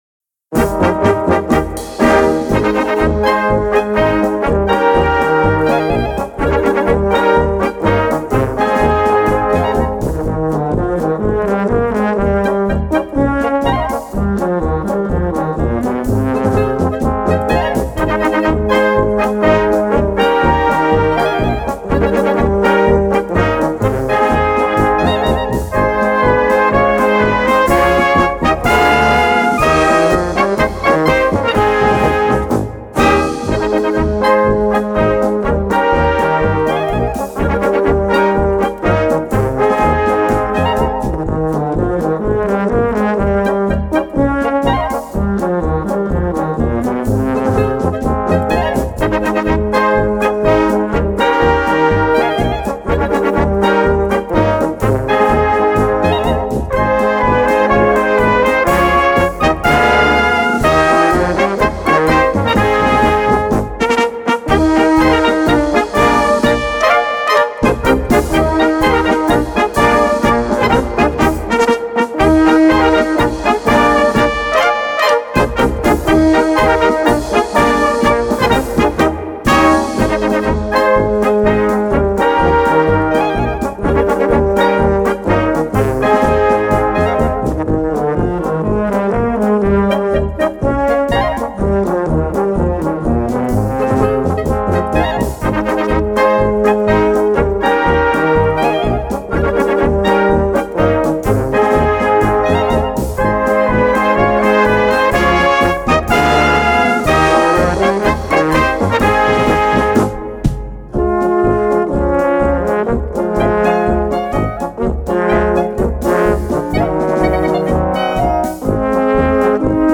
Categorie Harmonie/Fanfare/Brass-orkest
Subcategorie Polka
Bezetting Ha (harmonieorkest)